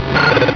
Cri de Feurisson dans Pokémon Rubis et Saphir.